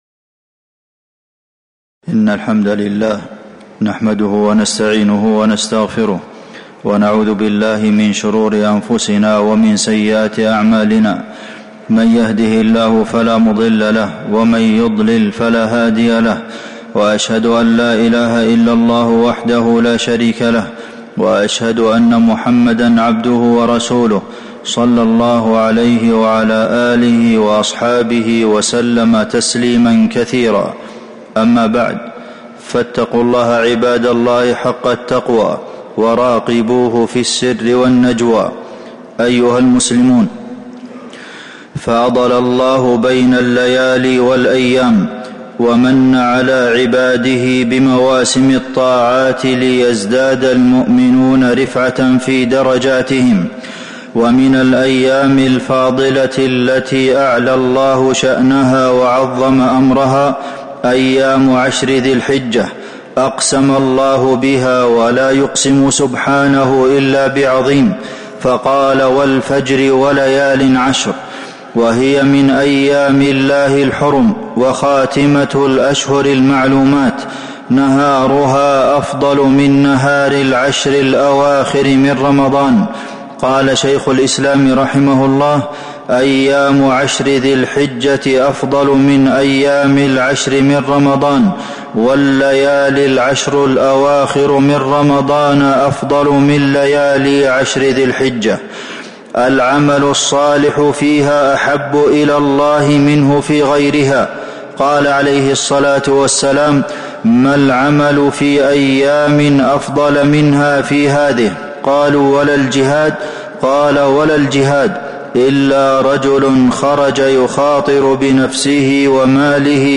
تاريخ النشر ٣ ذو الحجة ١٤٤٦ هـ المكان: المسجد النبوي الشيخ: فضيلة الشيخ د. عبدالمحسن بن محمد القاسم فضيلة الشيخ د. عبدالمحسن بن محمد القاسم وليالٍ عشر